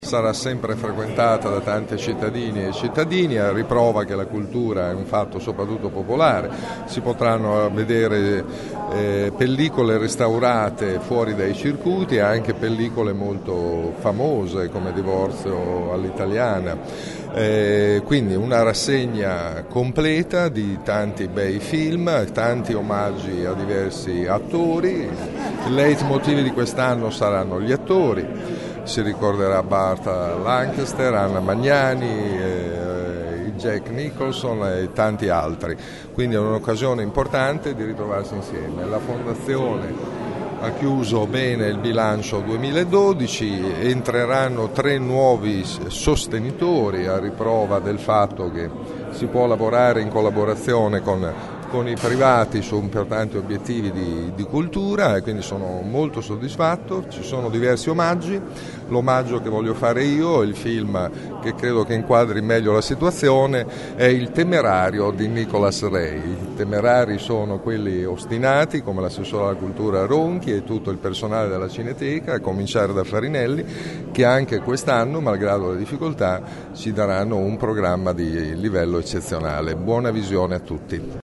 Soddisfatto del bilancio della Cineteca, il sindaco di Bologna, questa mattina presente in conferenza stampa assieme all’assessore comunale alla Cultura e al suo omologo regionale Massimo Mezzetti, ha voluto fare il suo personale augurio di “buona visione”